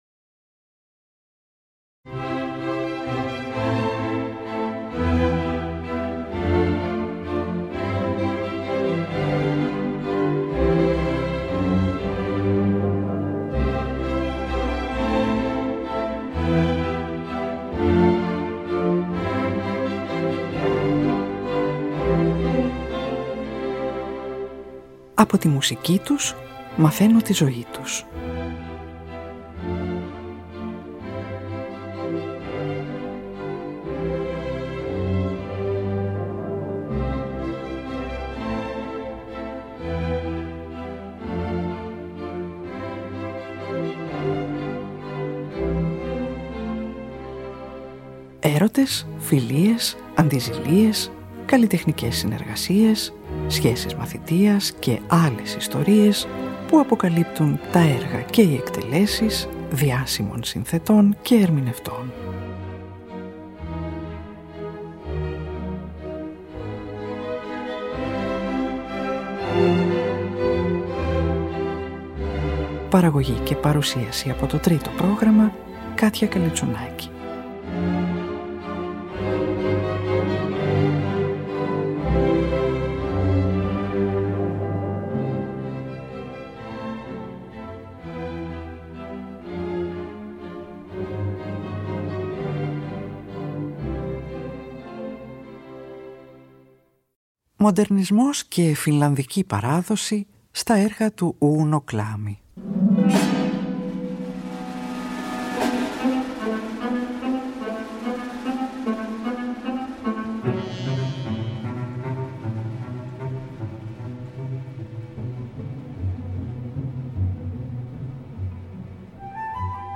για 2 βιολιά, τρομπέτα, κλαρινέτο και πιάνο
6μερής ορχηστρική σουίτα
ορχηστρικό rondo